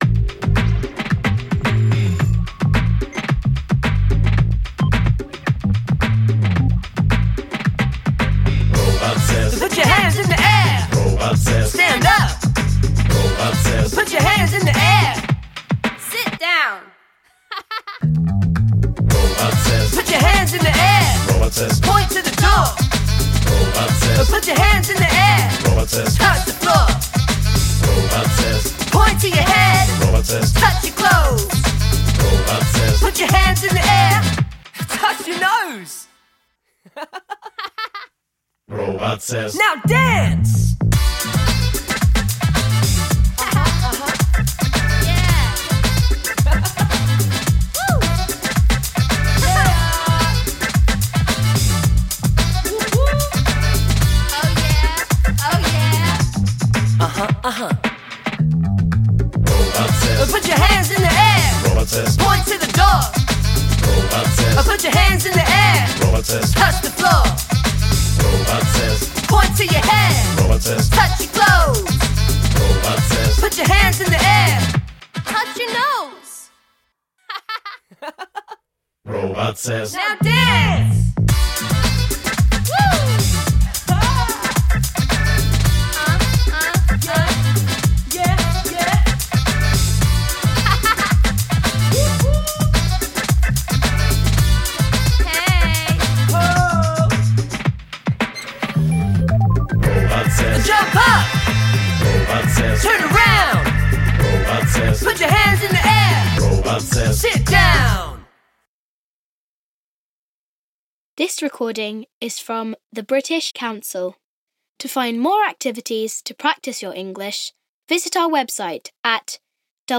Do you like action songs?
Robot songs